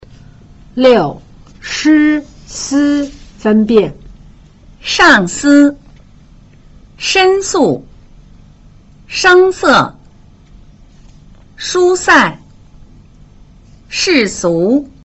1. 平舌音 z c s和 捲舌音 zh ch sh 的比較﹕
6） sh – s 分辨